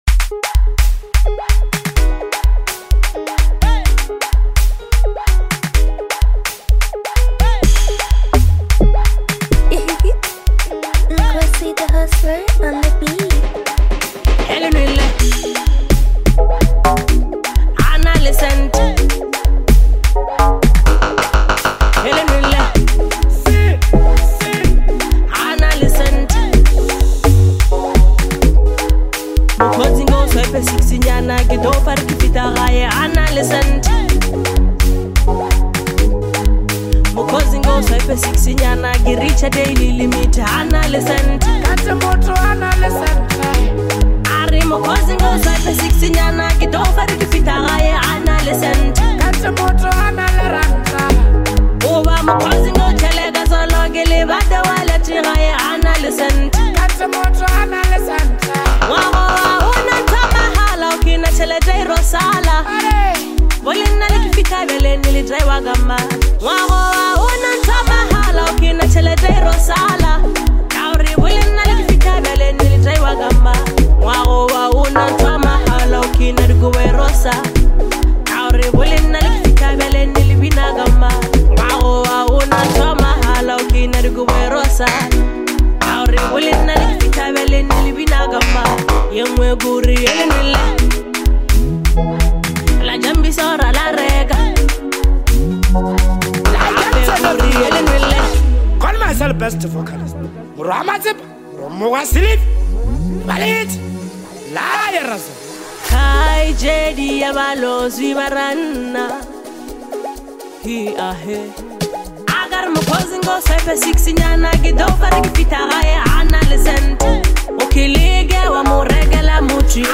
is a dynamic Afro fusion track